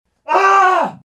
Звуки мужские
Мужчина ошпарился и вскрикнул